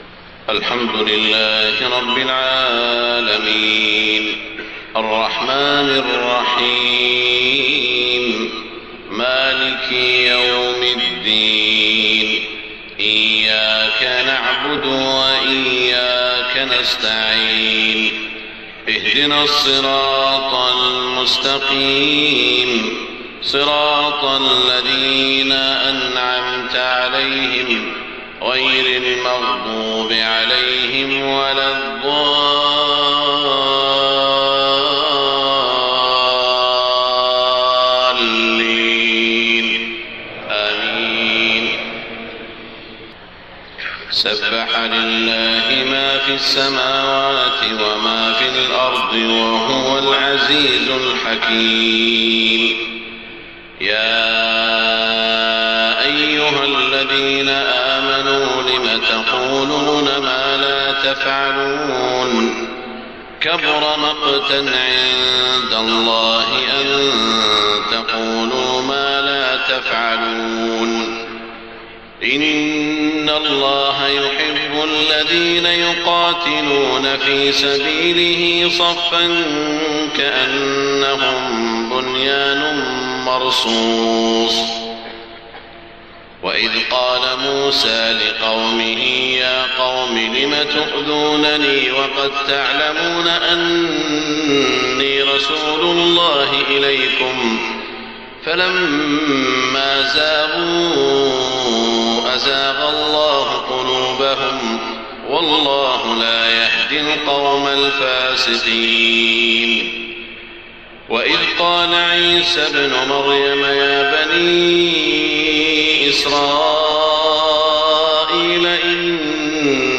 صلاة الفجر 22 ذو الحجة 1429هـ من سورة الصف > 1429 🕋 > الفروض - تلاوات الحرمين